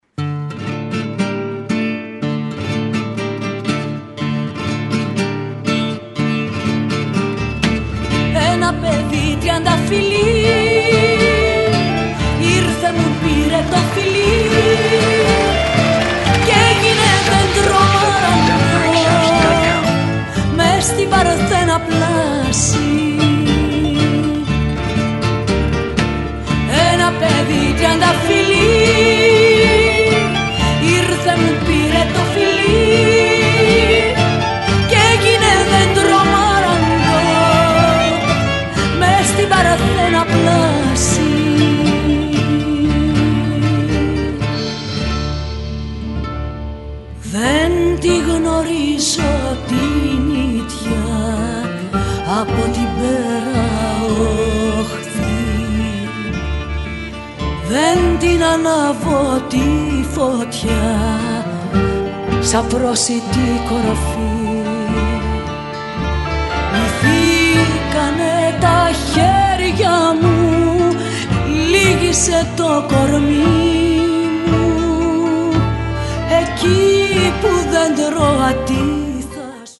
A live performance